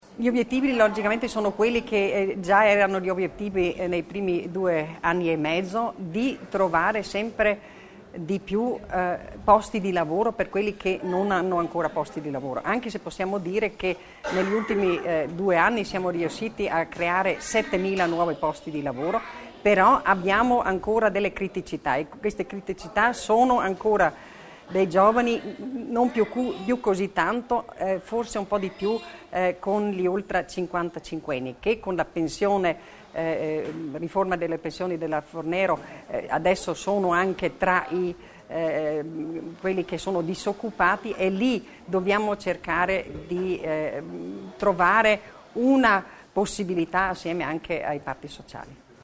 La terza parte della conferenza stampa dell’assessora Stocker è stata dedicata al tema della semplificazione per i cittadini e le imprese.